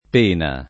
pena [ p % na ]